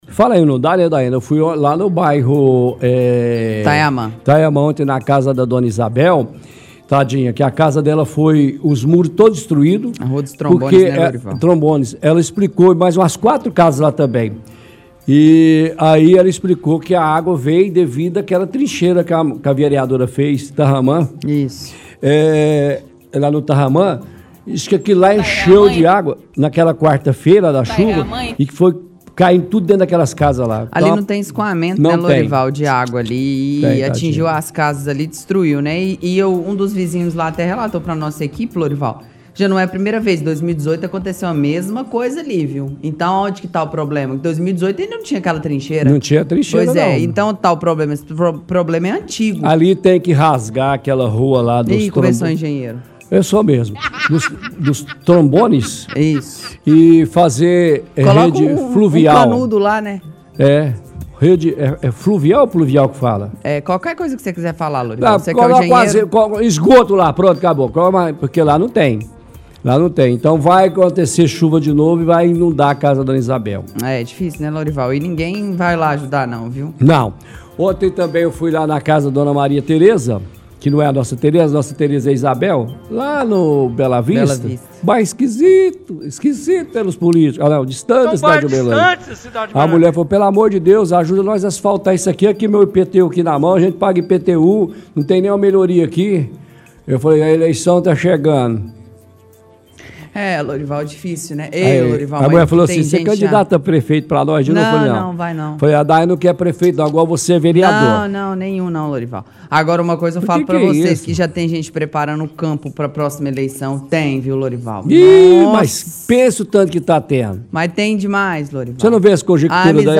– Apresentador e repórter comenta sobre águas de chuva no bairro Taiaman água dentro das casas de morares e diz que ninguém faz nada para resolver o problema.